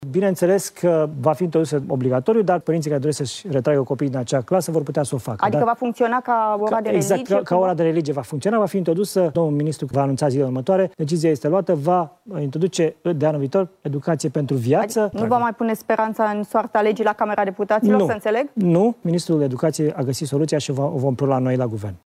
La TVR, șeful Executivului a explicat că orele vor fi predate tuturor elevilor, dar dacă un părinte nu acceptă ca fiul sau fiica să fie prezent, atunci poate cere ca elevul să nu stea în clasă.